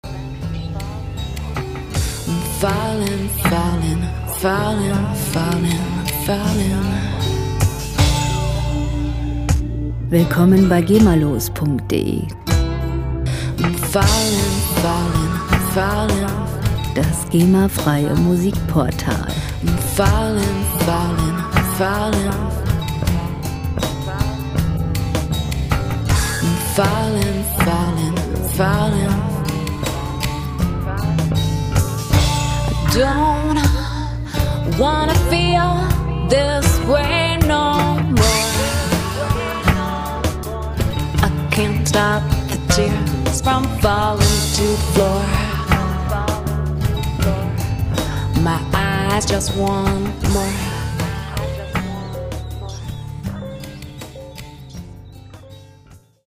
Rockmusik - Rockkonzert
Musikstil: Psychedelic Rock
Tempo: 79.5 bpm
Tonart: D-Moll
Charakter: verführerisch, lasziv